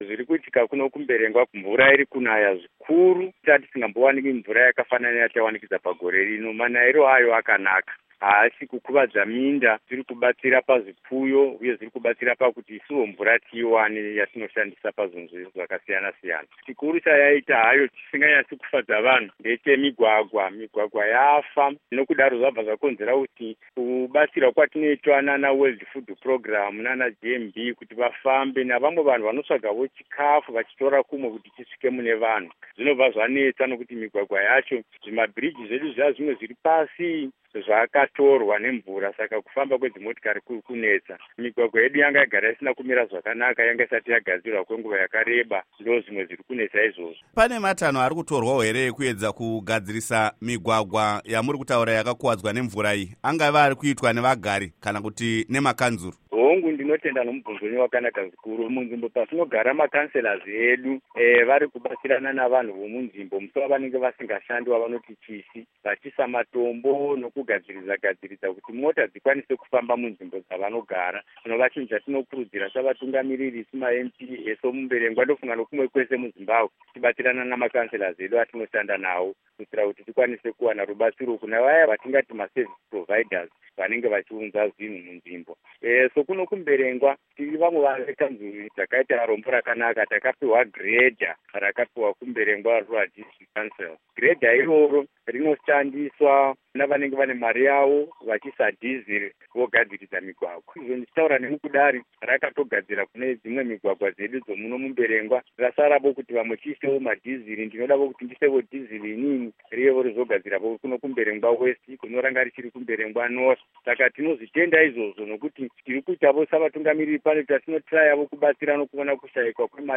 Hurukuro naVaJoram Gumbo